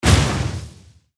archer_skill_eagledescent_grounhit.ogg